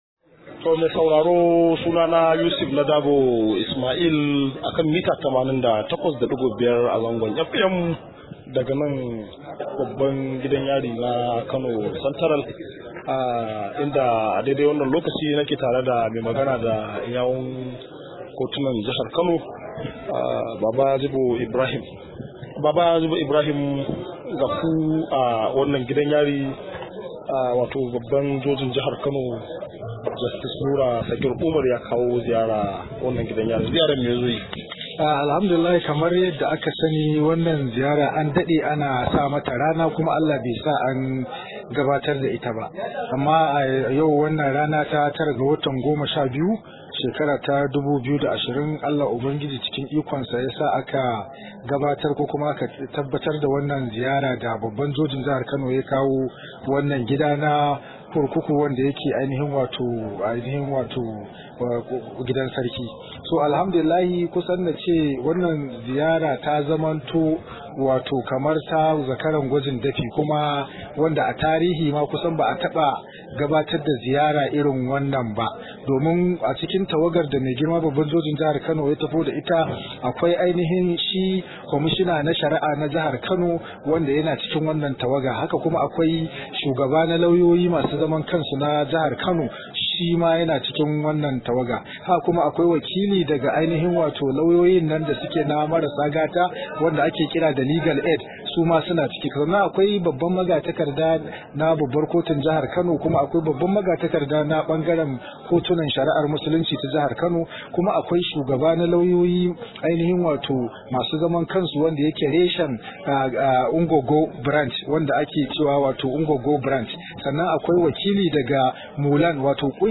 Rahoto